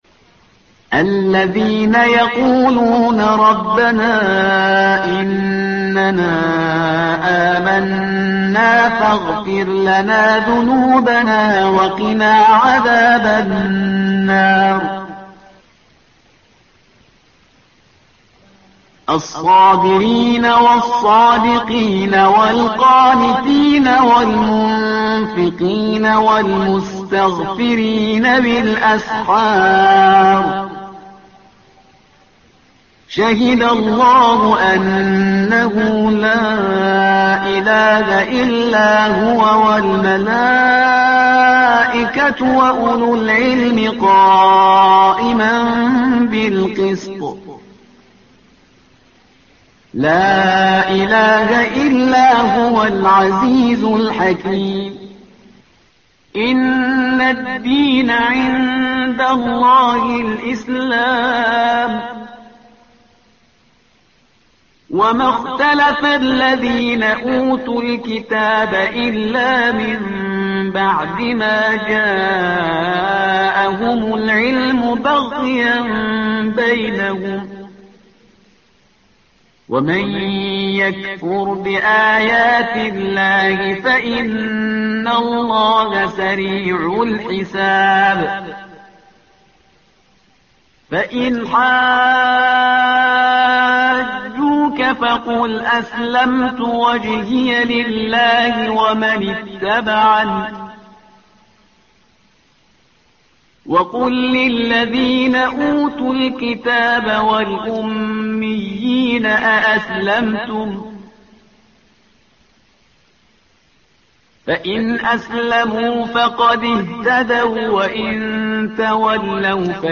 تحميل : الصفحة رقم 52 / القارئ شهريار برهيزكار / القرآن الكريم / موقع يا حسين